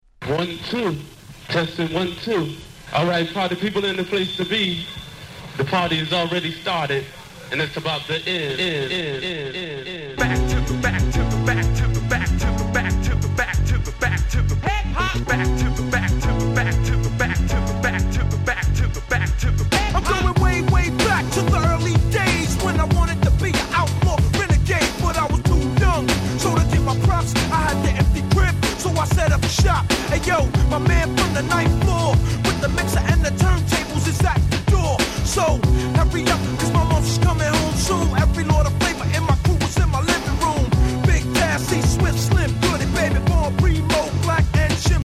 94' Hip Hop Classic !!
いつ聴いても本当に格好良い！！！！！！
90's Boom Bap